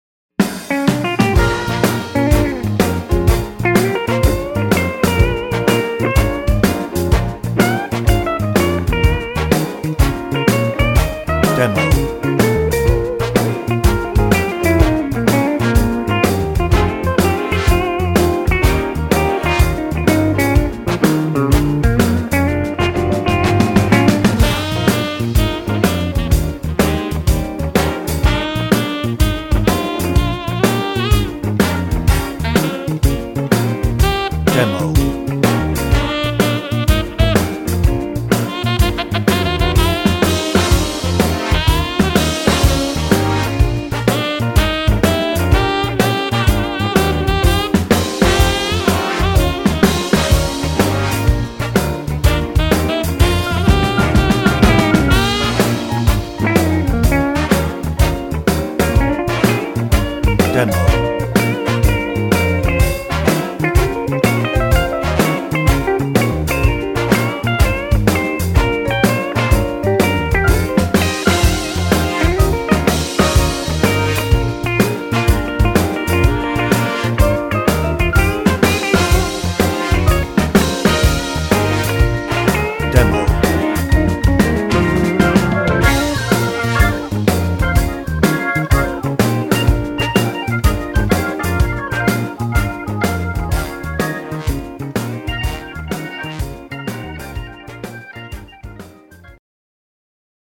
Hoedown
No ref. vocal
Instrumental